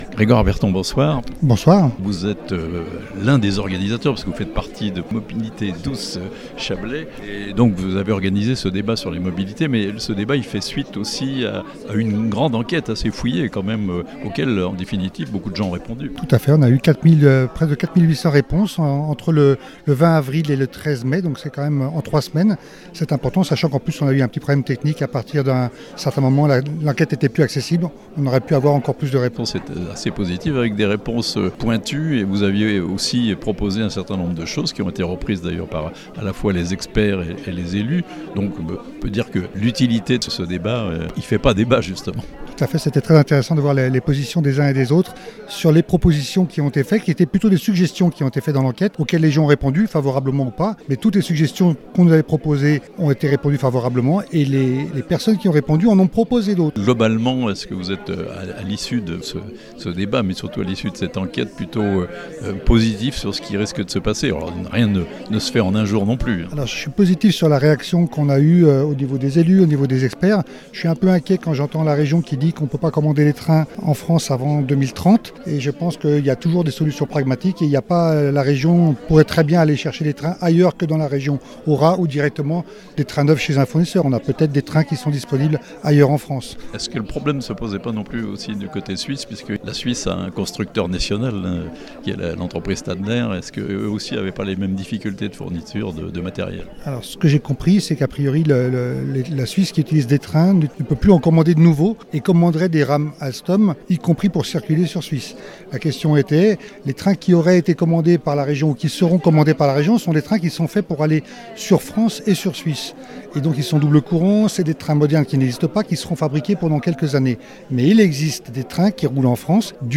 Une grande enquête et une conférence-débat sur les mobilités en Chablais (interview)